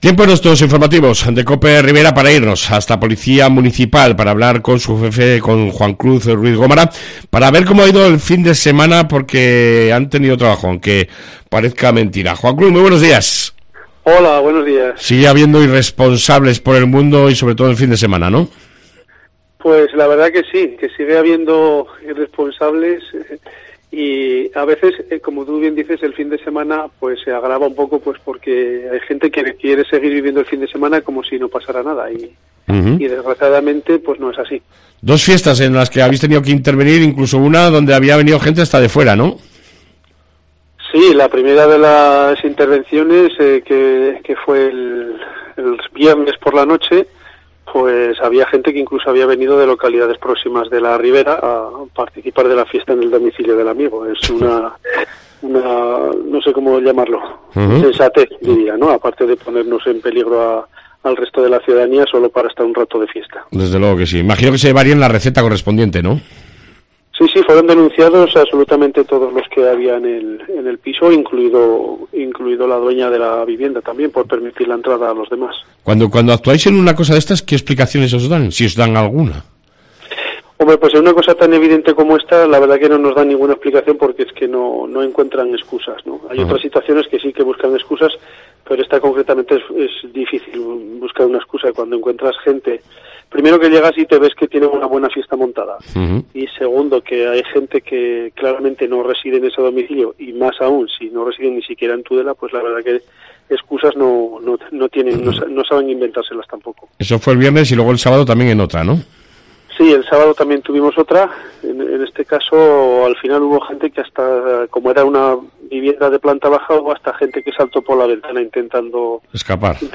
INFORMATIVOS COPE RIBERA 23/03